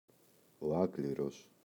άκληρος [Ꞌakliros]